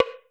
WOOD B L.wav